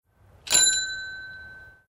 Timbre de una bicicleta (uno)
Sonidos: Transportes